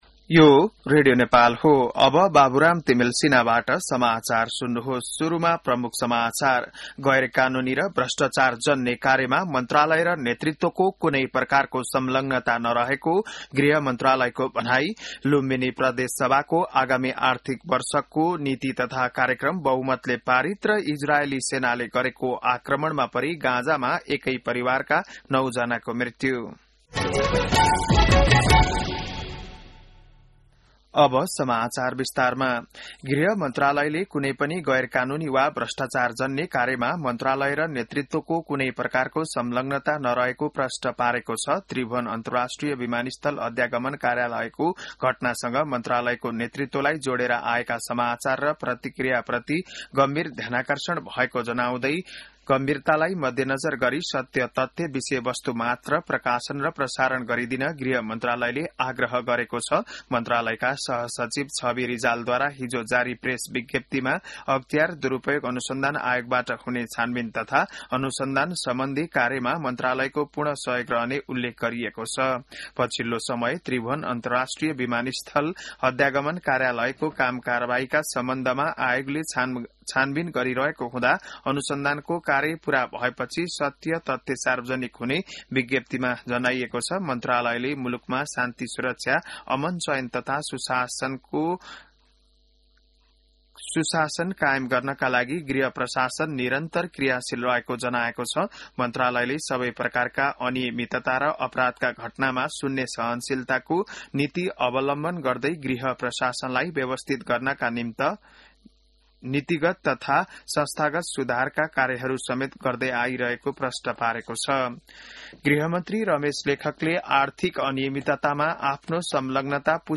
बिहान ९ बजेको नेपाली समाचार : ११ जेठ , २०८२